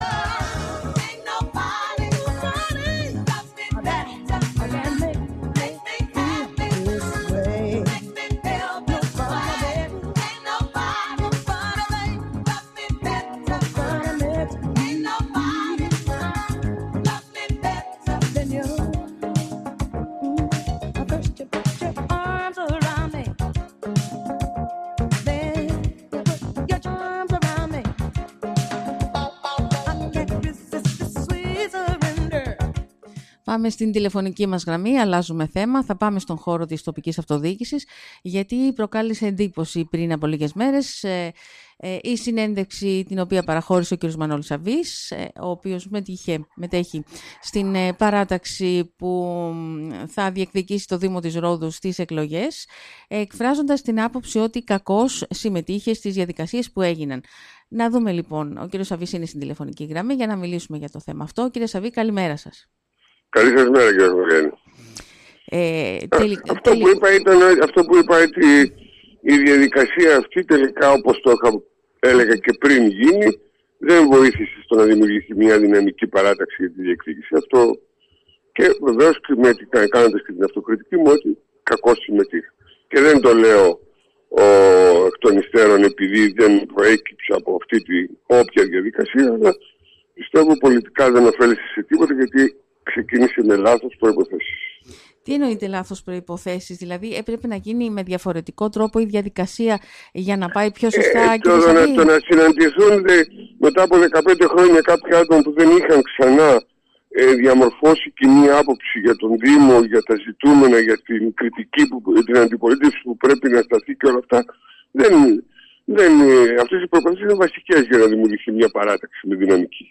Την άποψη ότι κακώς συμμετείχε στις διαδικασίες για την ανάδειξη αρχηγού στην παράταξη που θα διεκδικήσει το Δήμο Ρόδου στις προσεχείς εκλογές εξέφρασε μιλώντας  στον Sky ο πρώην δήμαρχος Νότιας Ρόδου κ.Μανώλης Σαββής.